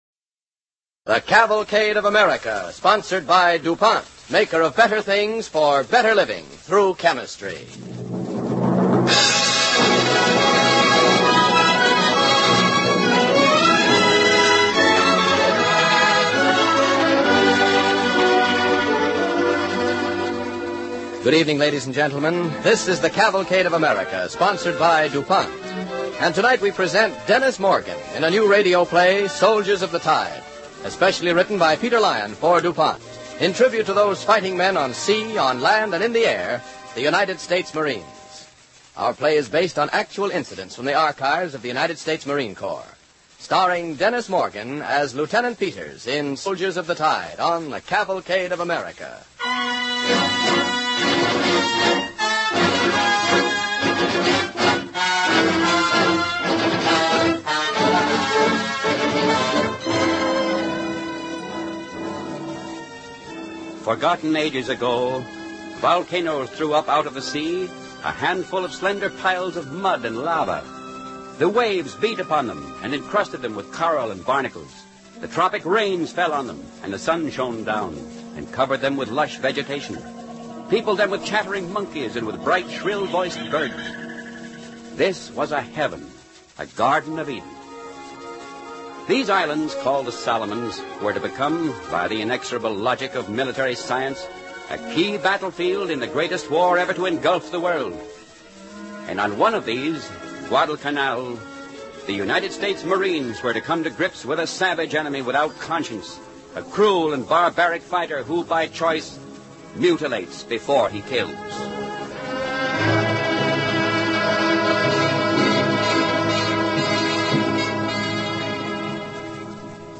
starring Dennis Morgan